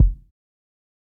BattleCatDopeKick.wav